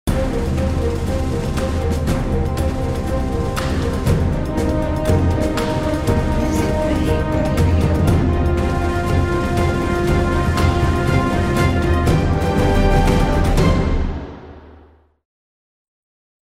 Epic royalty free music.